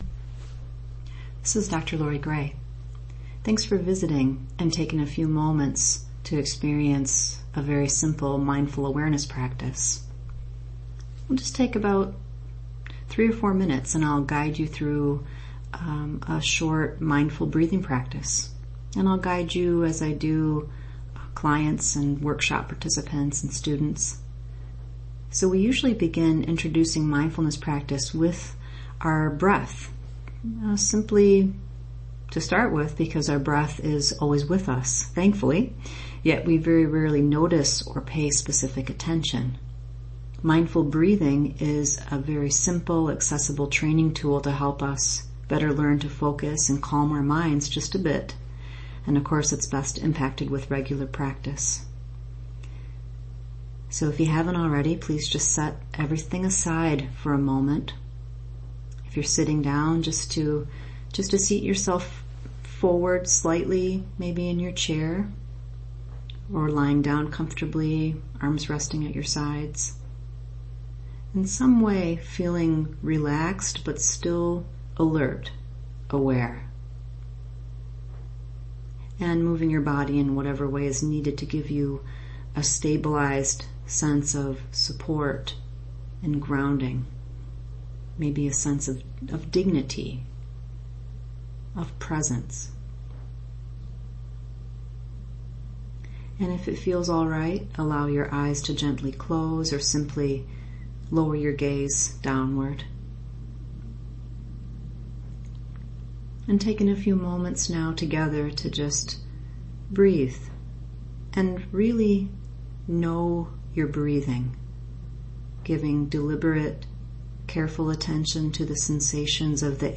Mindfulness Meditation